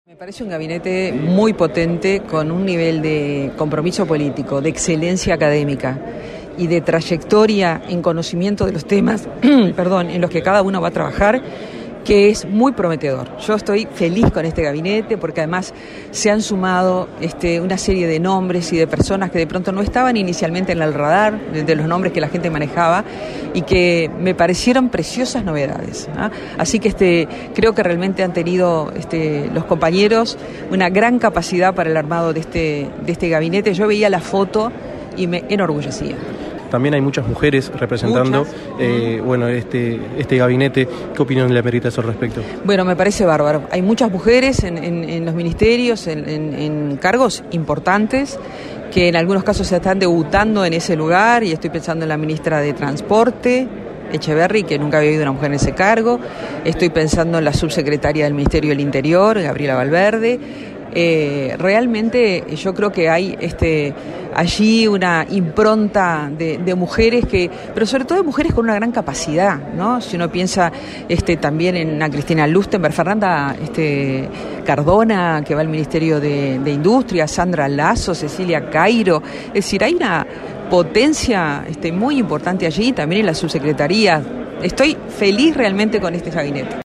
La senadora electa por la lista 609, Blanca Rodríguez, en entrevista con 970 Noticias, fue consultada sobre la participación de las mujeres en el gabinete presentado el pasado lunes por el presidente electo Yamandú Orsi.